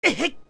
OOT_AdultLink_Hurt2.wav